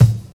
20 KICK 3.wav